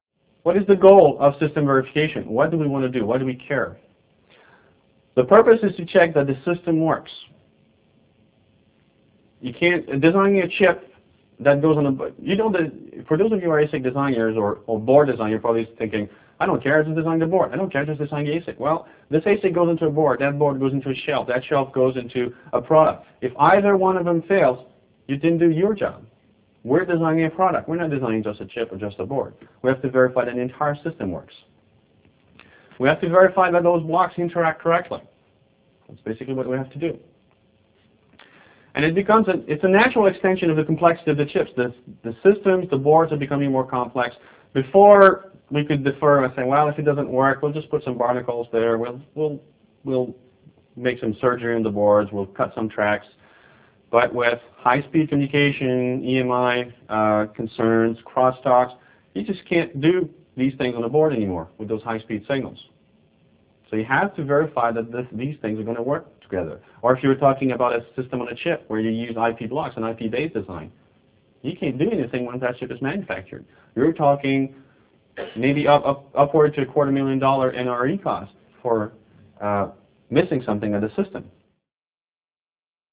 Online Seminar: A Strategic Process for System Level Verification, Slide 5 of 44